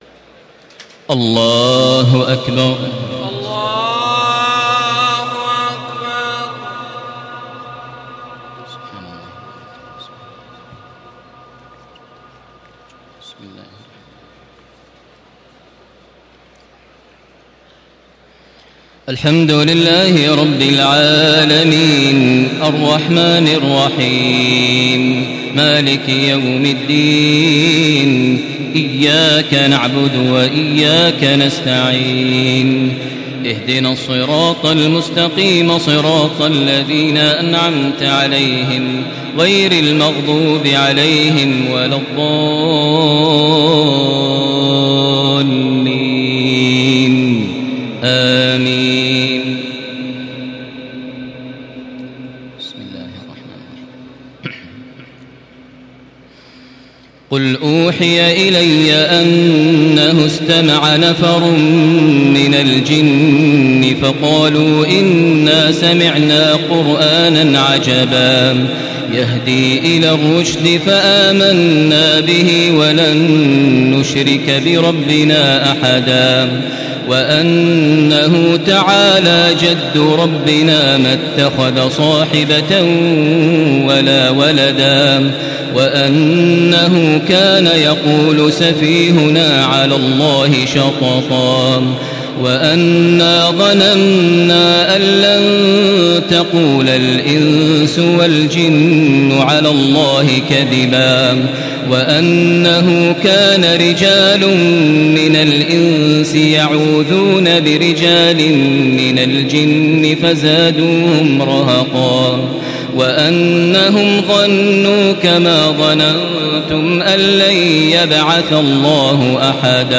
Surah Al-Jinn MP3 in the Voice of Makkah Taraweeh 1435 in Hafs Narration
Murattal Hafs An Asim